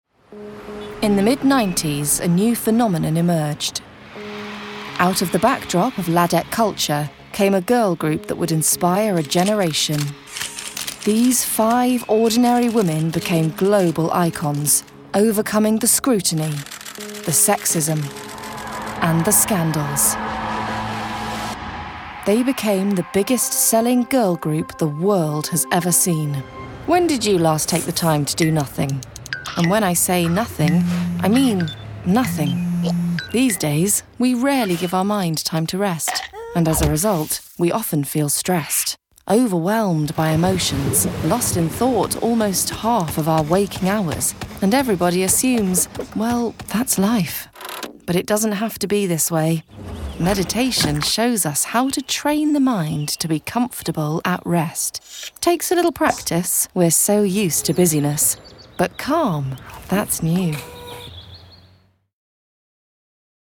Narration Reel
• Native Accent: Geordie, R.P
• Home Studio
With an instinctive grasp of character, she too has a talent for the off-beat and the quirky.